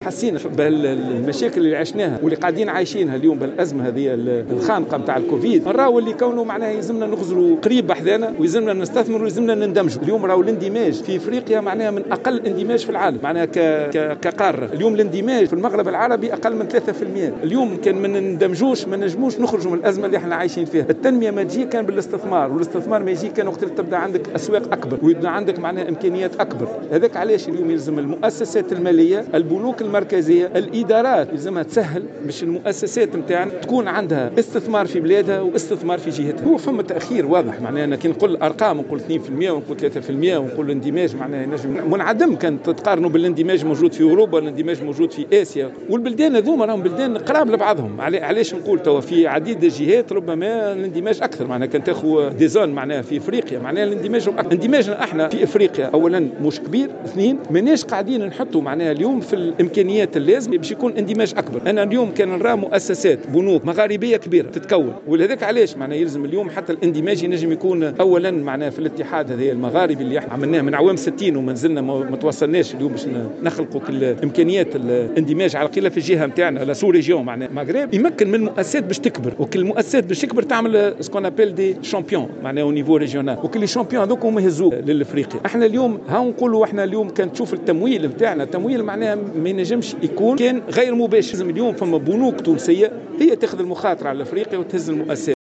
وأضاف في تصريح لوسائل الإعلام، على هامش مشاركته في الدورة الرابعة للمؤتمر الدولي لتمويل الاستثمار والتجارة في إفريقيا (فيتا 2021)، أنّه من المفروض أن يساعد البنك المركزي هذه المؤسسات البنكية على الاستثمار في القارّة. كما لفت إلى أن كل المؤسسات البنكية الموجودة في أفريقيا هي، التّي تستثمر عن طريق مشاريع كبرى بتمويل من البنك الافريقي للتنمية.